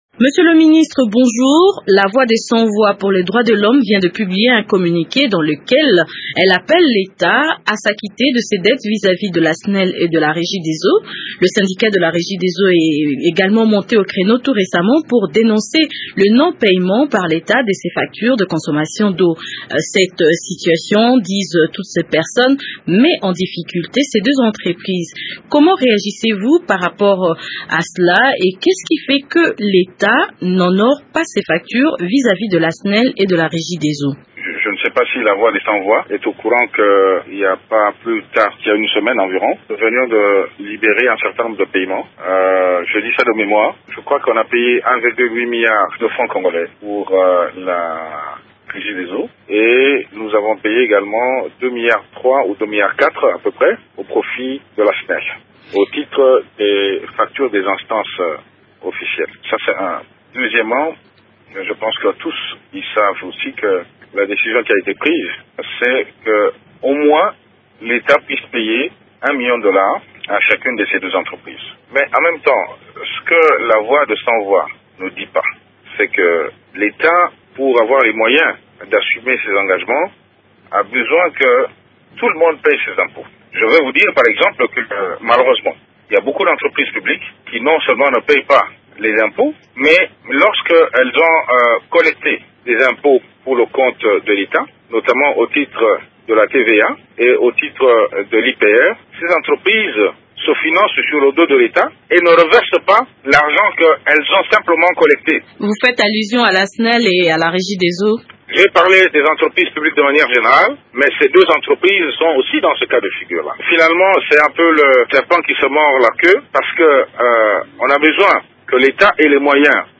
Le ministre délégué aux finances Patrice Kitebi réagit à ce communiqué.